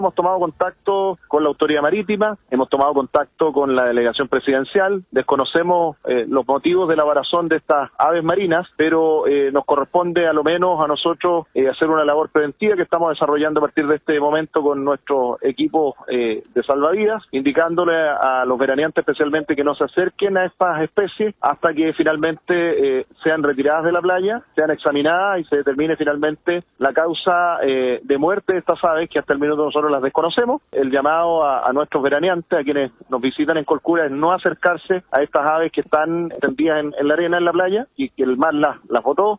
Se trató de una situación que se extendió en el 70% de dicho borde costero, según dijo el alcalde Jaime Vásquez.